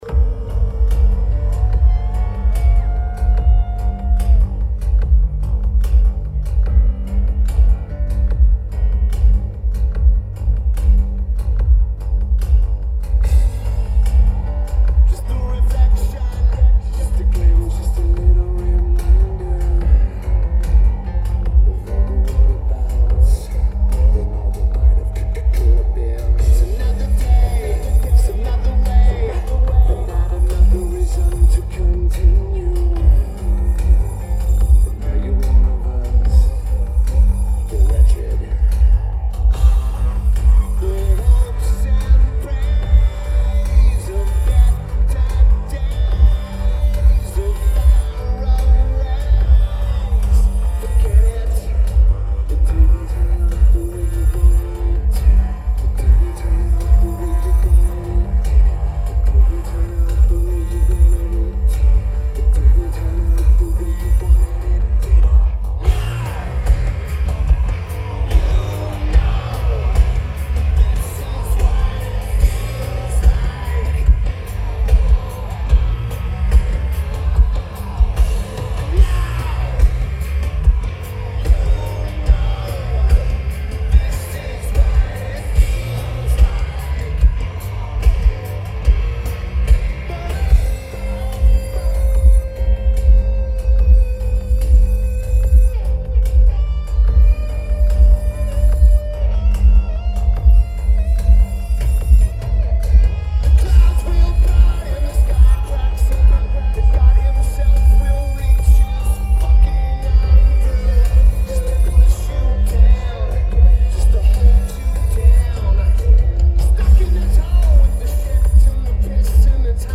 Keyboards/Bass/Backing Vocals
Drums
Guitar